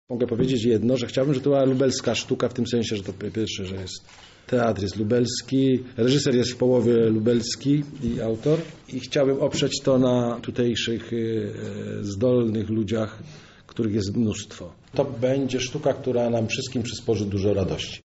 Mówi reżyser, Juliusz Machulski.